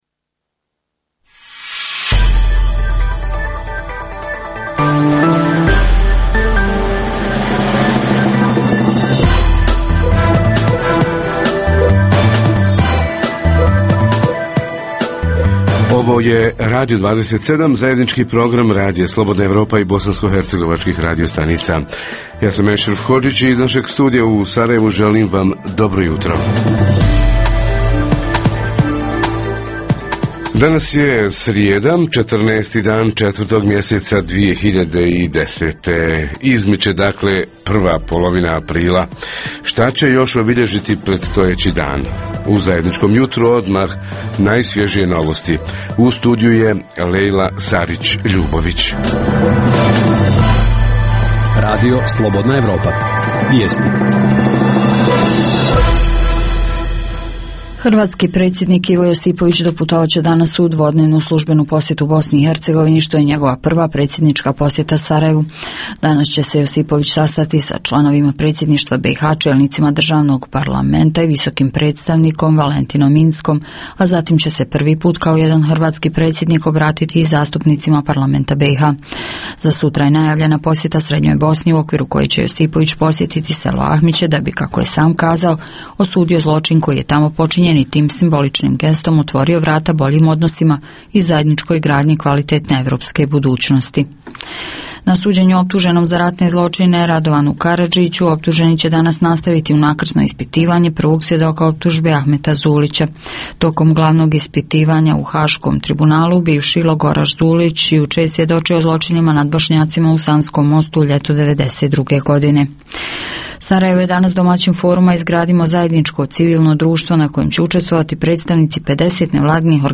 BiH, njena privreda i izvoz - kako dobiti izvozni poticaj? Reporteri iz cijele BiH javljaju o najaktuelnijim događajima u njihovim sredinama.